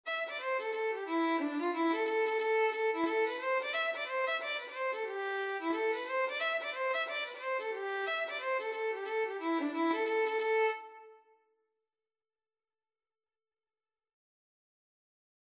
A minor (Sounding Pitch) (View more A minor Music for Violin )
2/4 (View more 2/4 Music)
Violin  (View more Intermediate Violin Music)
Traditional (View more Traditional Violin Music)
Irish